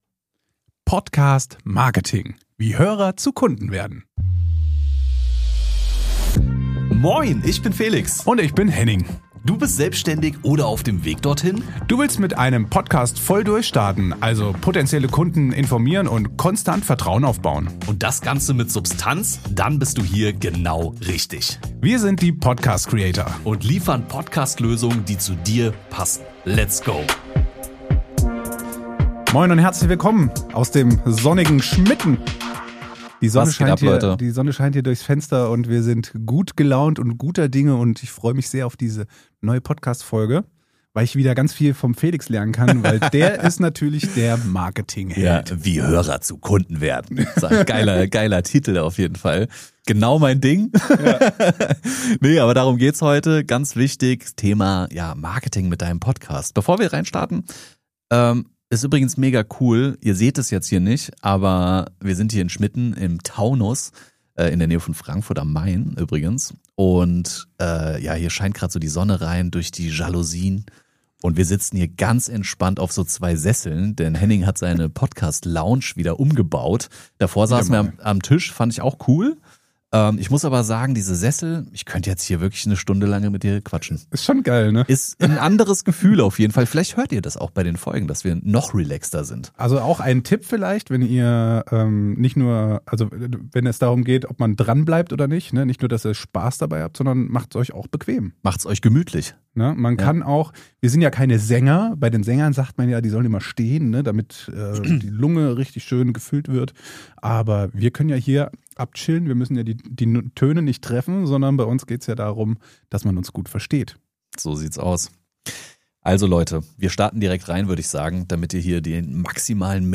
Wir sprechen darüber, warum Podcast-Marketing nachhaltiger ist als Social Media, wie Vertrauen durch lange Hörzeit entsteht und wie du mit SEO-optimierten Podcast-Folgen bei Spotify, Apple Podcasts und sogar bei Google gefunden wirst. Aufgenommen in Schmitten im Taunus (nahe Frankfurt am Main) teilen wir praxisnahe Einblicke aus Marketing & Podcast-Produktion und zeigen dir, warum Qualität wichtiger ist als Quantität – und wie dein Podcast für dich verkauft, ohne Sales-Druck.